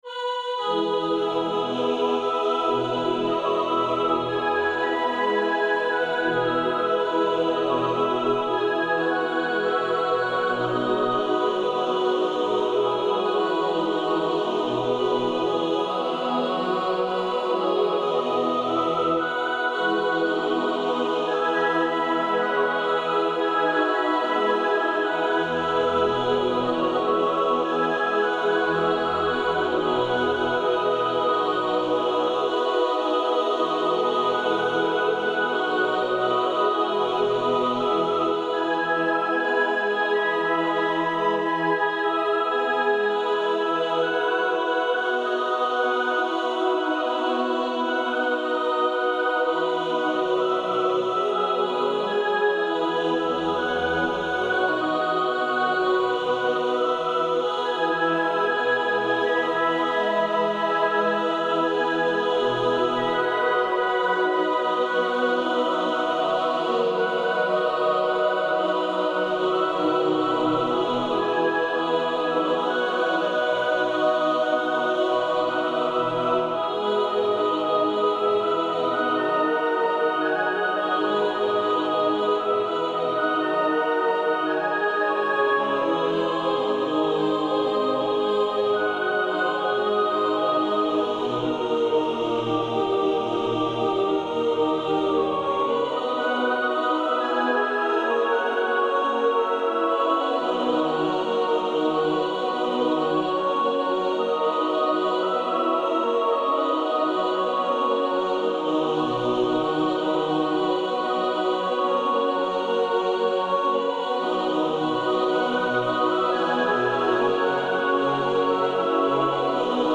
Trellis (synthesised vocals only) - Orchestrated: 19th Aug - 16th September 2012.
Oddly writing it complicated wasn't entirely successful, and it seemed to sound more convincing when I basically used the same notes for all the parts and staggered them.